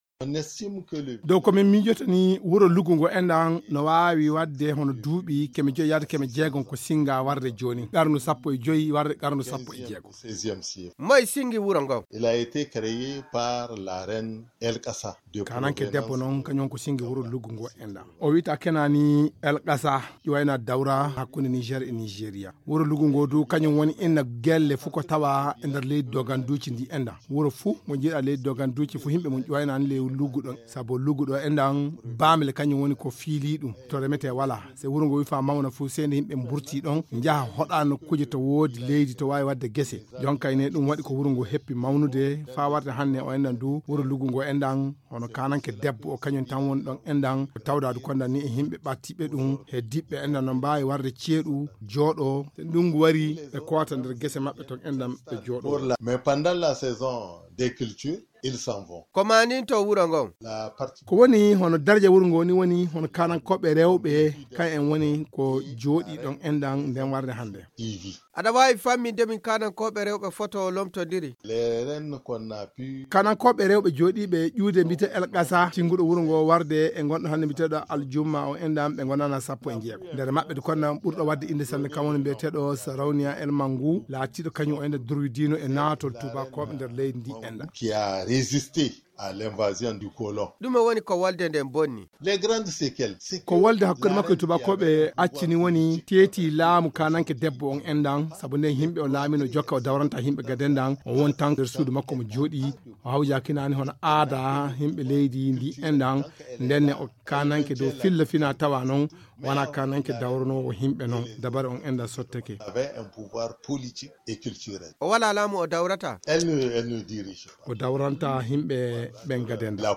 [Magazine] L’histoire de Lougou, le village de la SARAOUNIA - Studio Kalangou - Au rythme du Niger